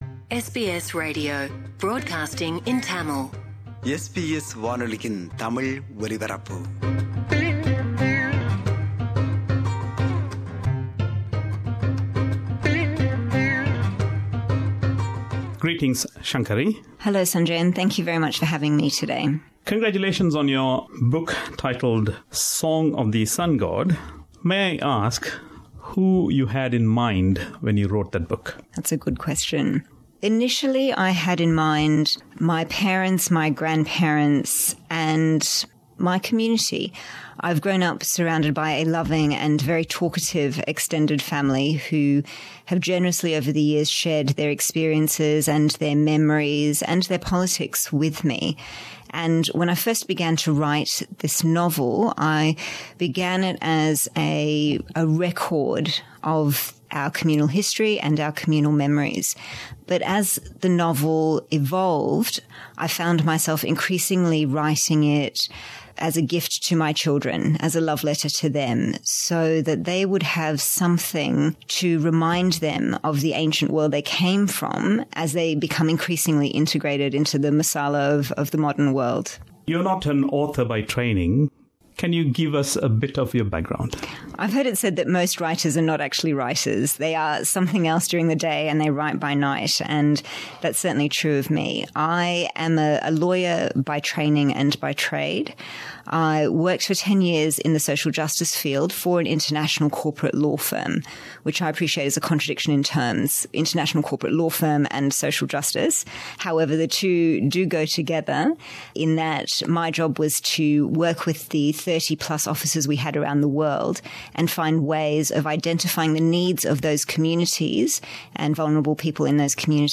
This is the first of the two-part interview.